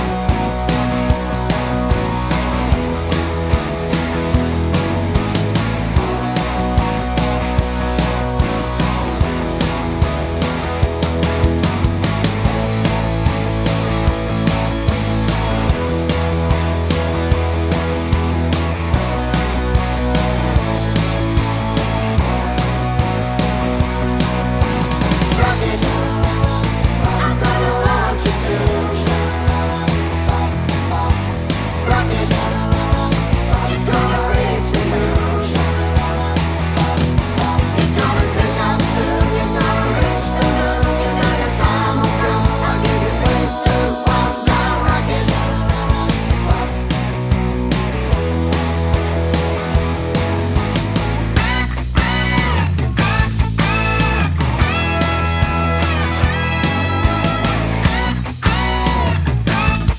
no Backing Vocals Glam Rock 4:09 Buy £1.50